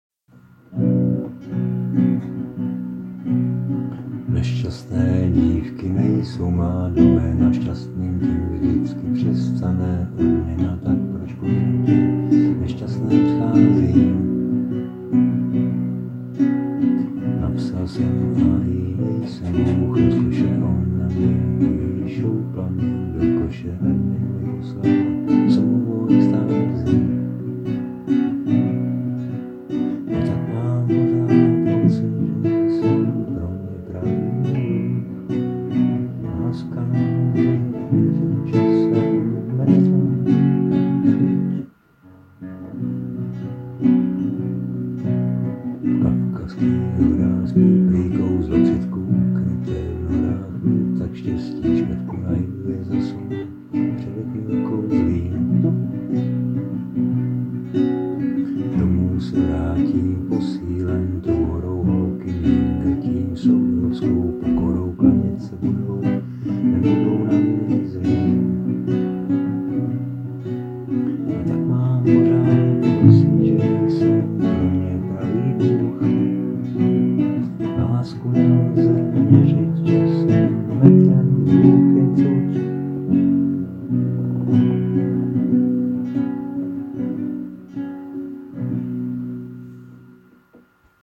Anotace: Písničkobásničkokravinka č.4
tak šťastné spasení :) je fajn dát do toho hlas, má to větší sílu
A zase ten zastřený hlas...